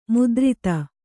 ♪ mudrita